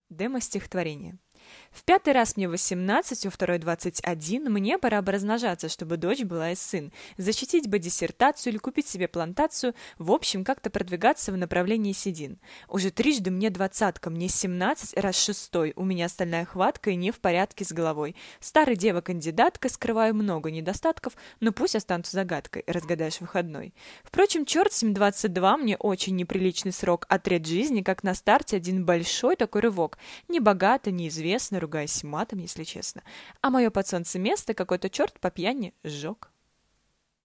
Демо - стих-2.7-40.mp3